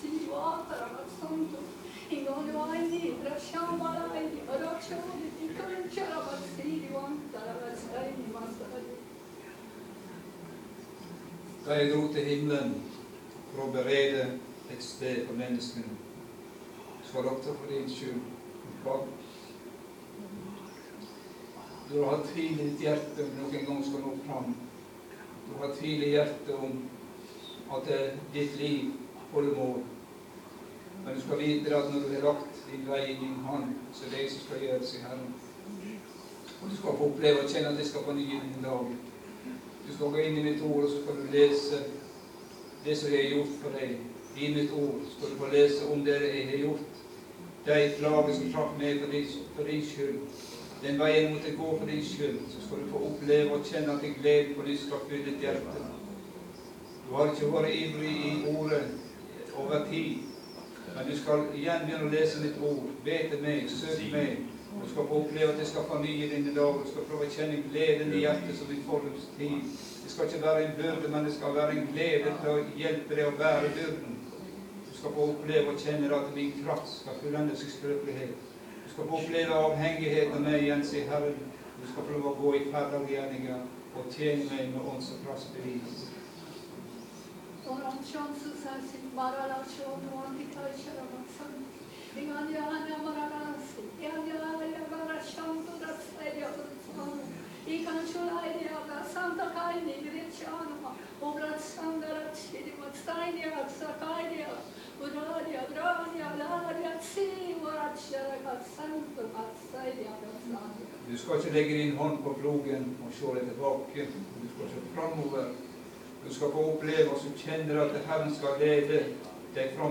Stå i den sigeren som Jesus har vunne, ikkje i eiga kraft, men i Guds kraft. Møte på Sion Åheim 4.1.2015.
Bodskap gjennom tungetale og tyding.